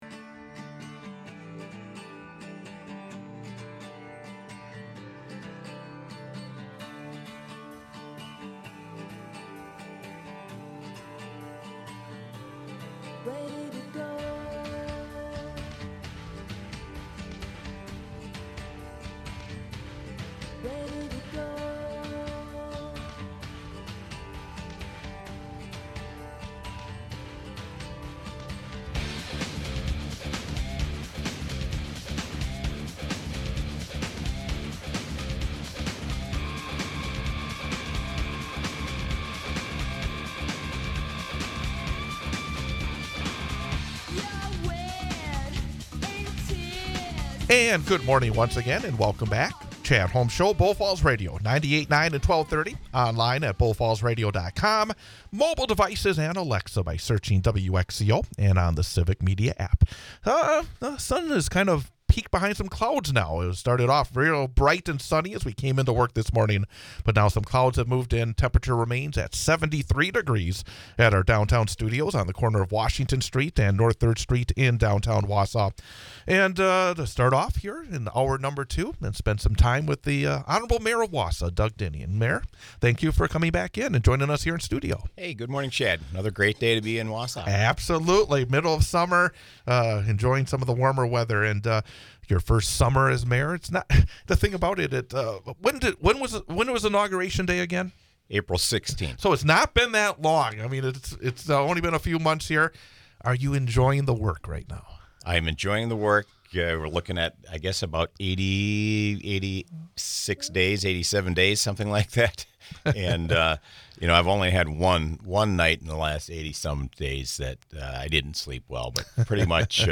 Wausau mayor Doug Diny joins us for most of the hour.